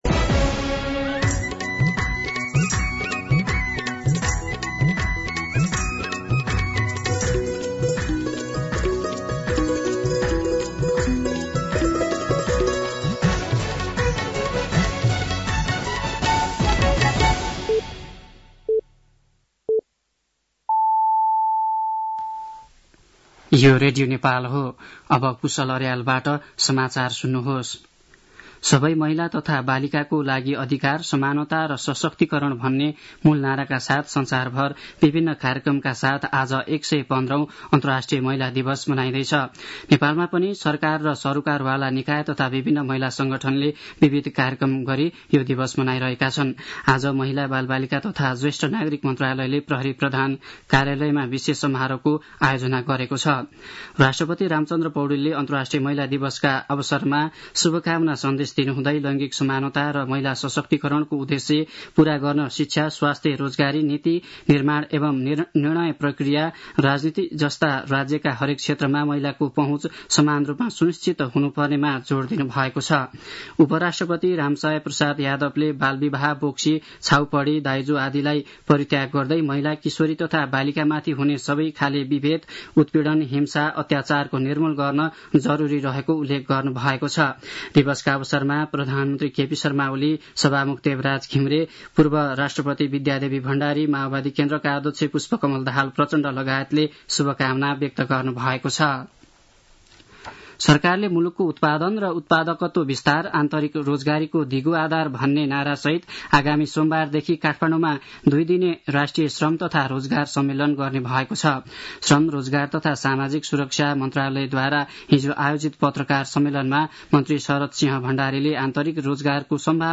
दिउँसो १ बजेको नेपाली समाचार : २५ फागुन , २०८१